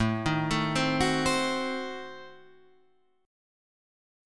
A Diminished 7th